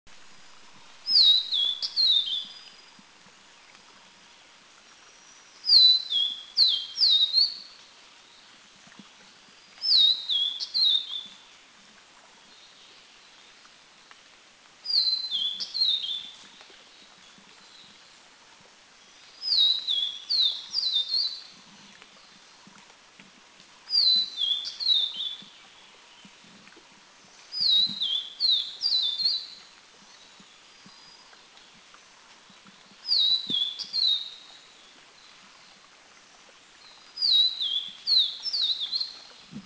Vivid Niltava Niltava vivida  vivida
B2A_VividNiltavaBluegate410_SDW.mp3